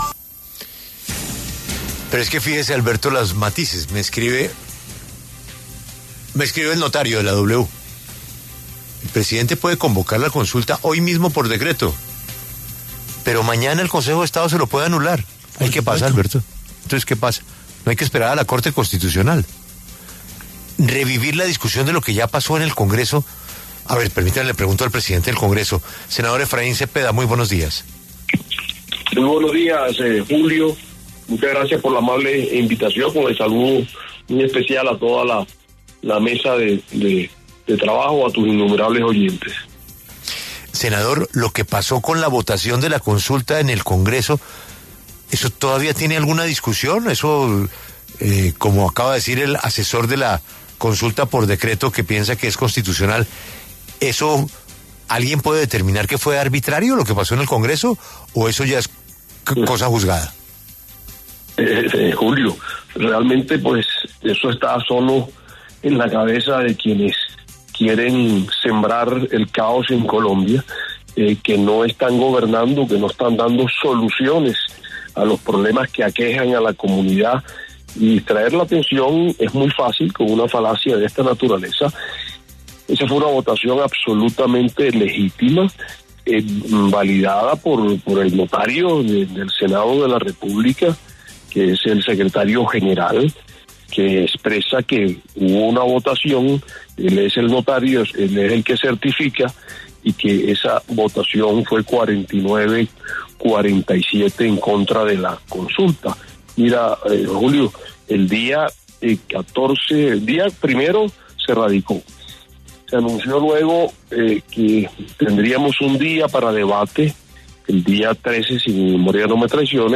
Habló en La W el presidente del Senado, el conservador Efraín Cepeda, quien rechazó el decreto con el que el Gobierno busca convocar una consulta popular.